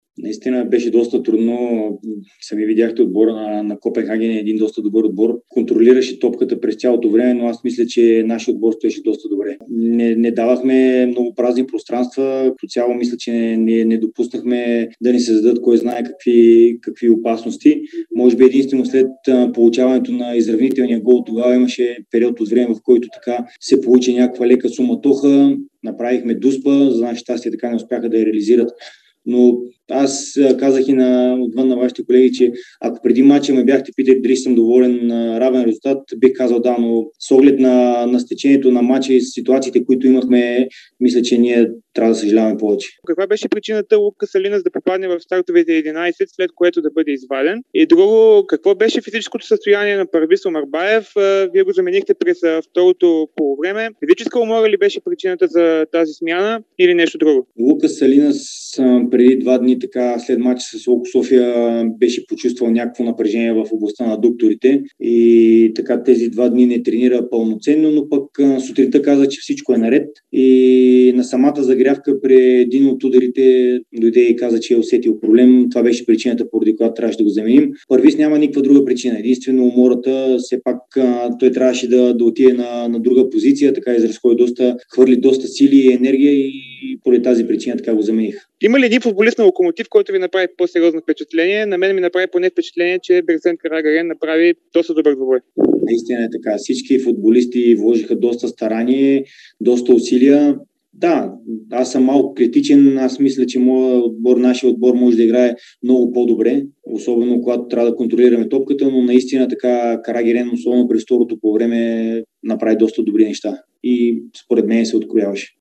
Старши треньорът на Локомотив Александър Тунчев коментира равенството 1:1 срещу Копенхаген от третия квалификационен кръг на Лига на конференциите. Пловдивчани напълно запазват шансовете си за реванша.